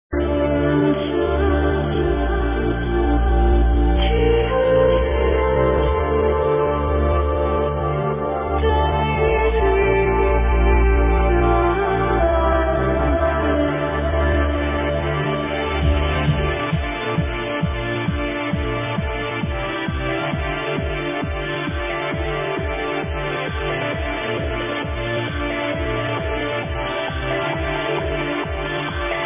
can anyone ID this Vocal Track?